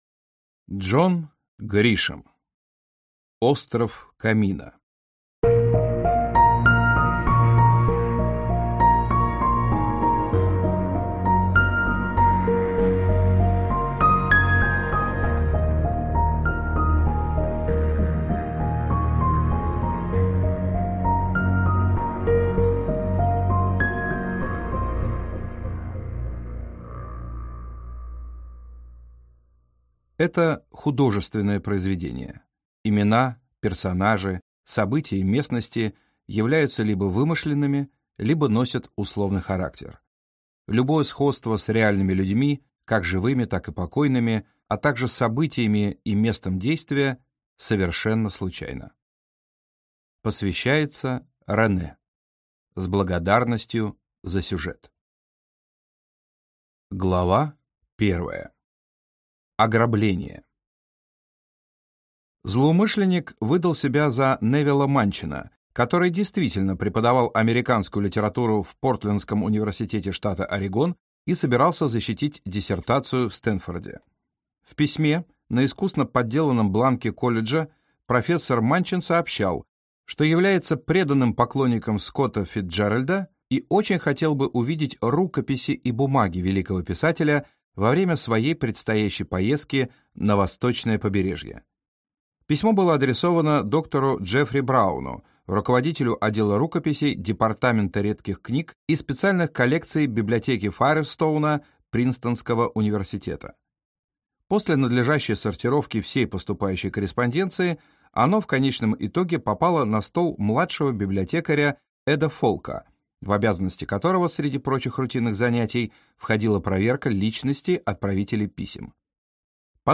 Аудиокнига Остров Камино | Библиотека аудиокниг